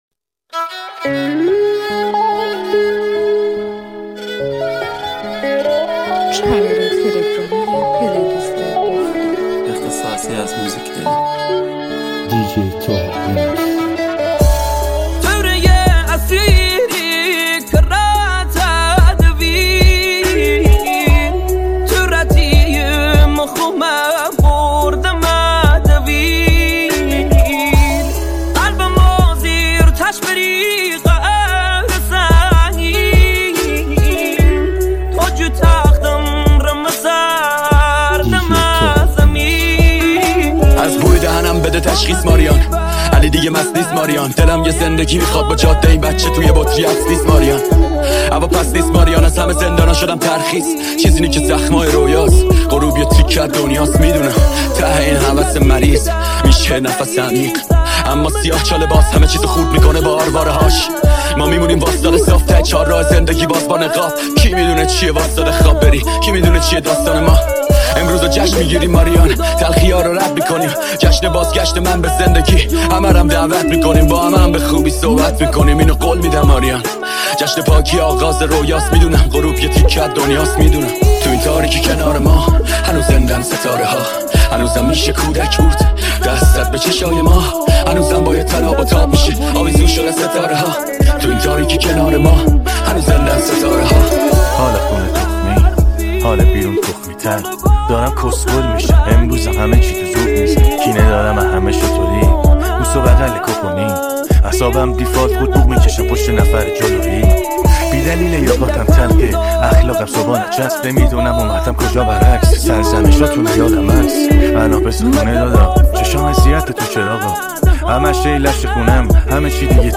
Exclusive Remix
ریمیکس رپ